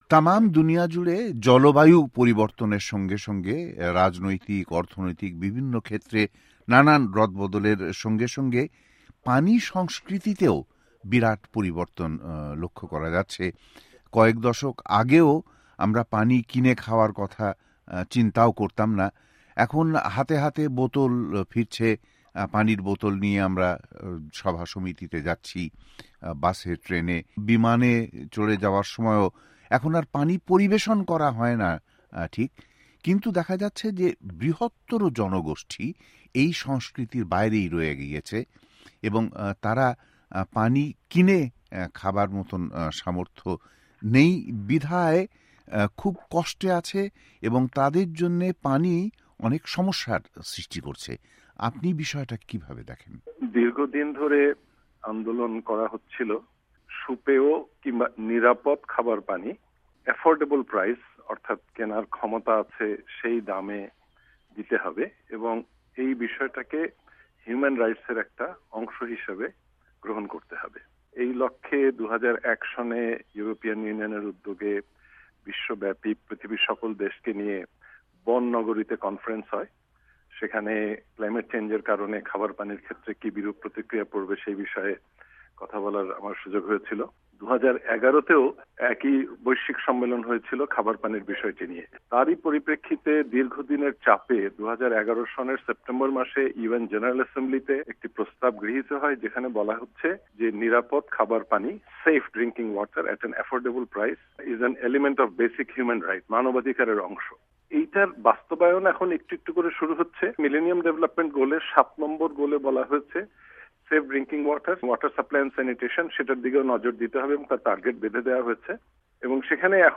সাক্ষাৎকার